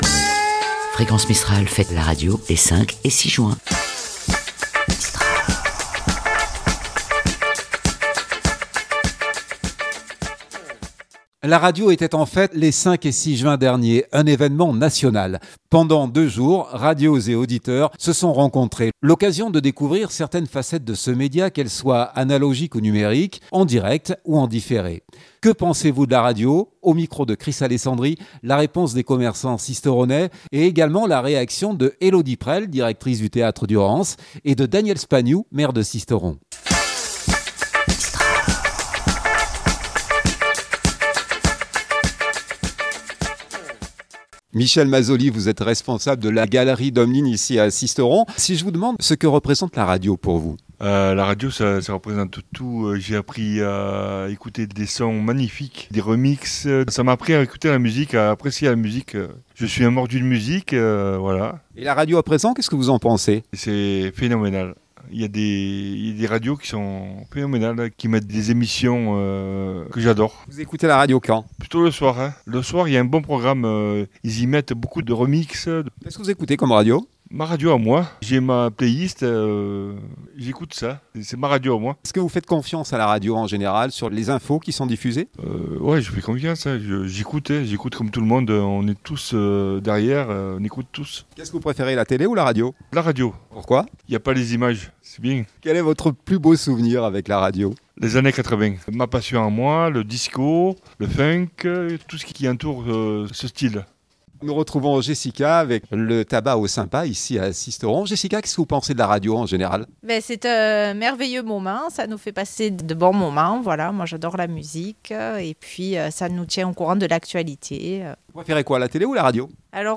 la réponse des commerçants Sisteronais